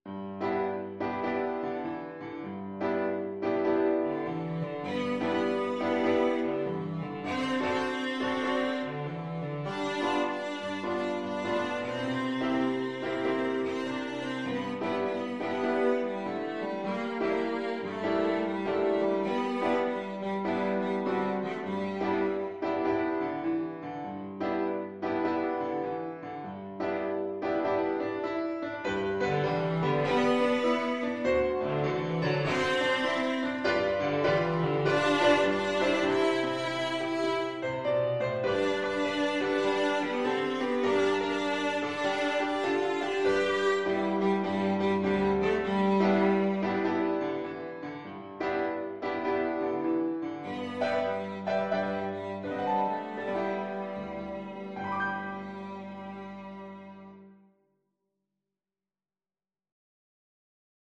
Traditional Trad. Tohora nui Cello version
G major (Sounding Pitch) (View more G major Music for Cello )
Moderate swing
Traditional (View more Traditional Cello Music)
tohora_nui_VLC.mp3